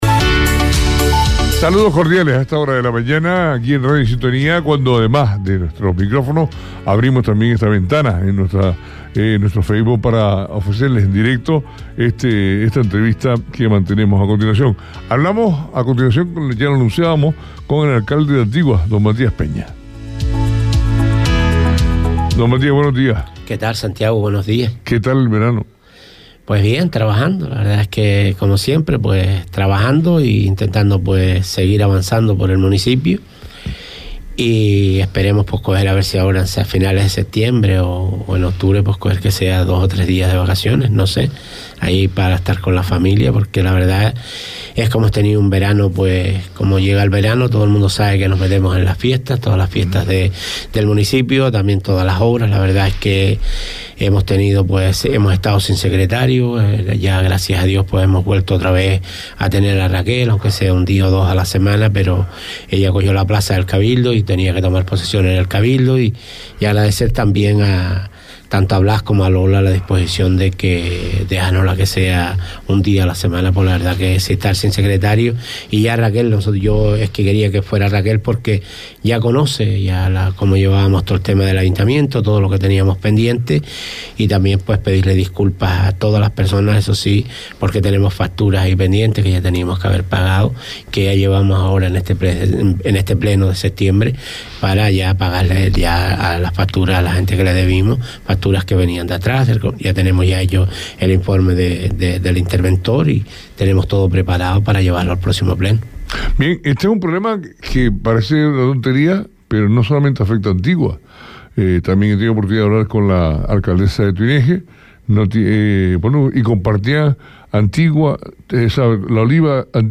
Entrevista a Matías Peña, alcalde de Antigua.